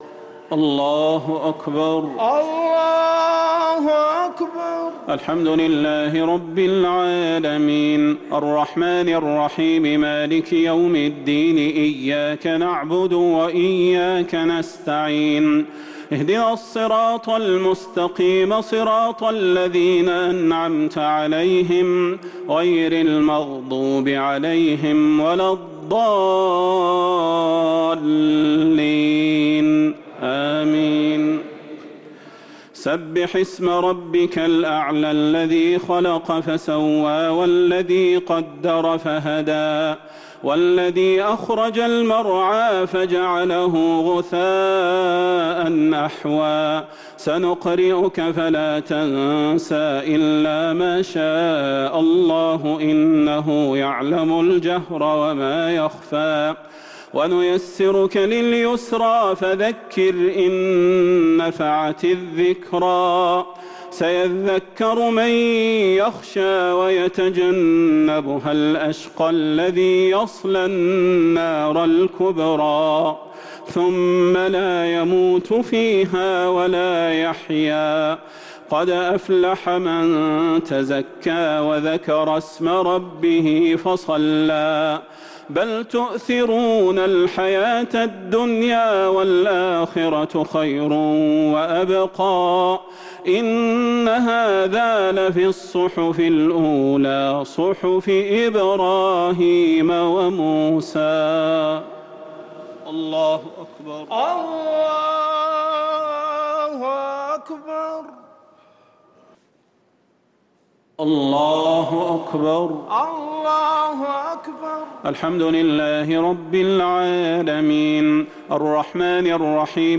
صلاة التراويح ليلة 20 رمضان 1444 للقارئ صلاح البدير - الشفع والوتر - صلاة التراويح